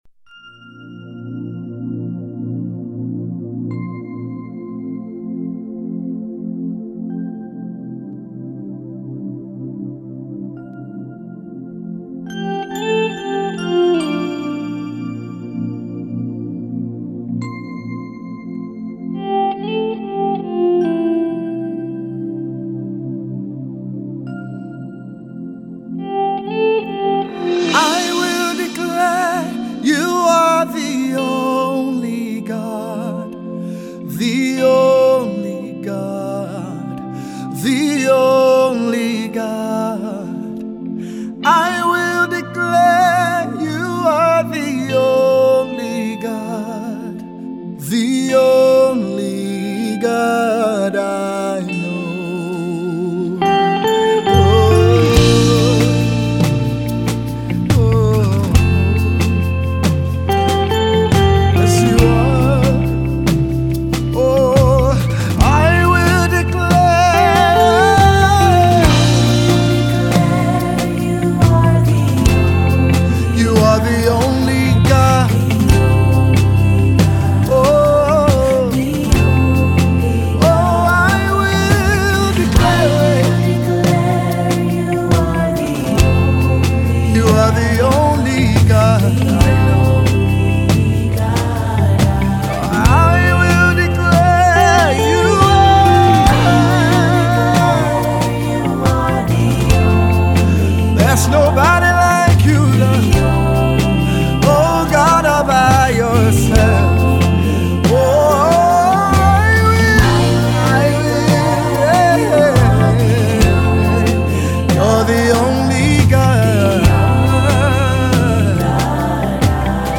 a heart-felt song that will bring you to a place of total